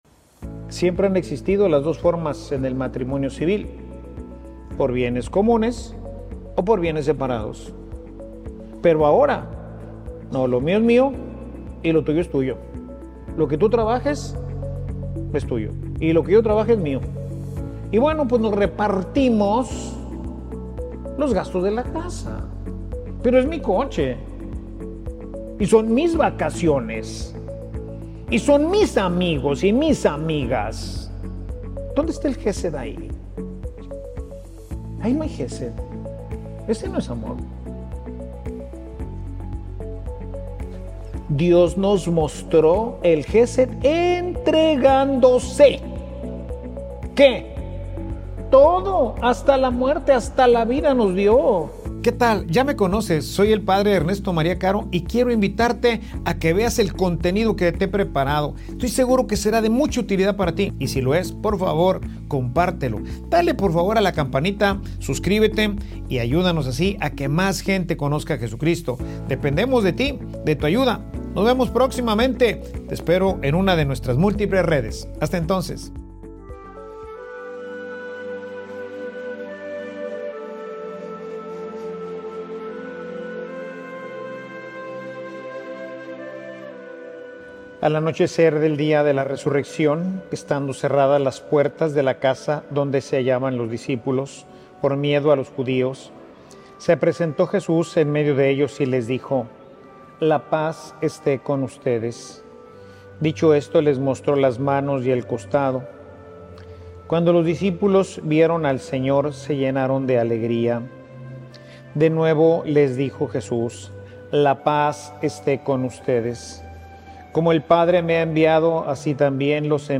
Homilia_Dios_se_hace_visible_en_nuestro_amor.mp3